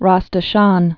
(räs də-shän)